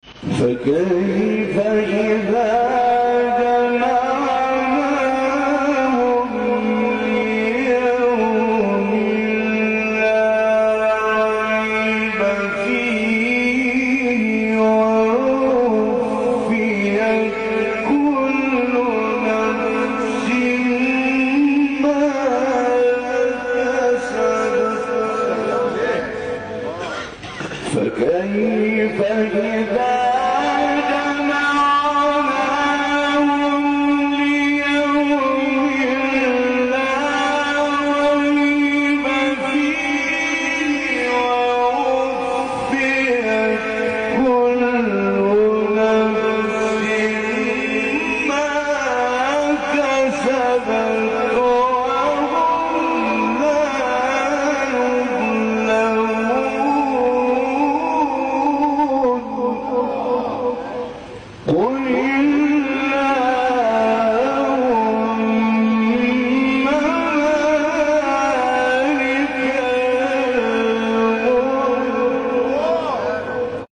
تلاوت زیبای 25-26 سوره آل عمران طنطاوی | نغمات قرآن
مقام : بیات